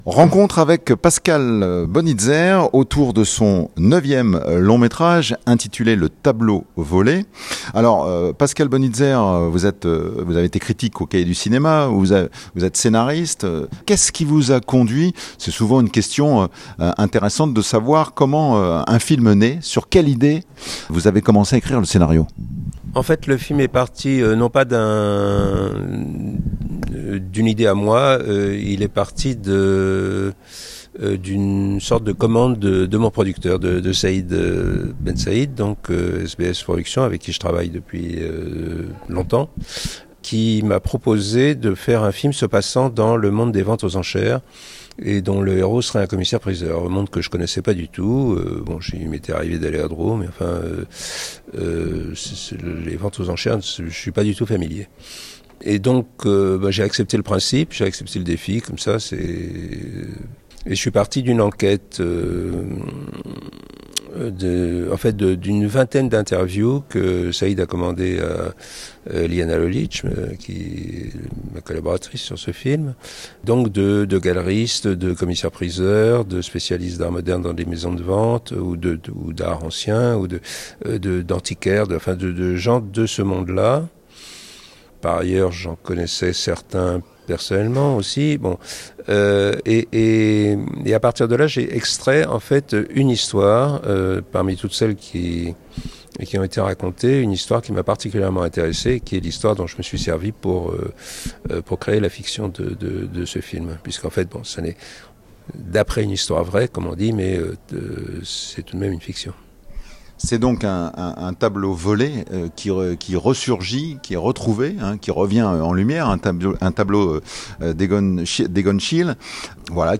Lors de cette rencontre avec le réalisateur Pascal Bonitzer